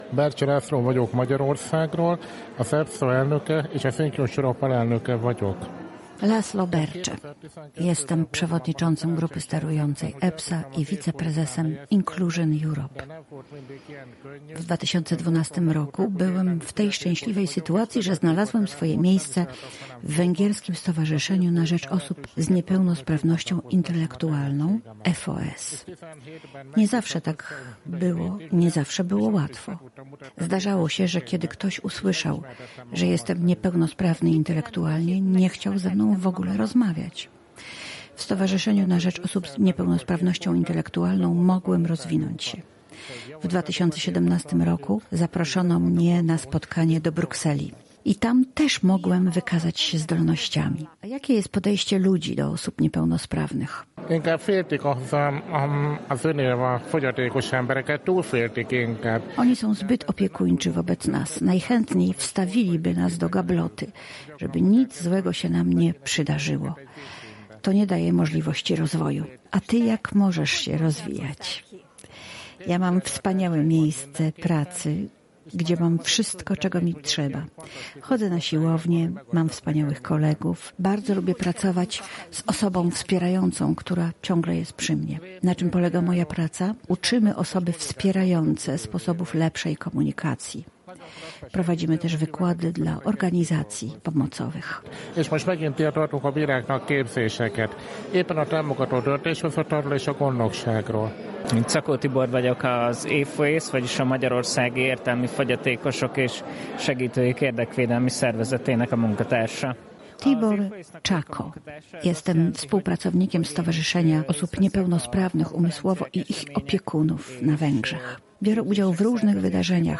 „Usłysz nasze głosy”. Pod tym hasłem w Podkarpackim Urzędzie Wojewódzkim w Rzeszowie spotkali się self-adwokaci z Podkarpacia oraz goście z Węgier, Zgierza i Warszawy. Osoby z niepełnosprawnością chcą być pełnoprawną częścią społeczeństwa i działalność self-adwokatów ma temu służyć.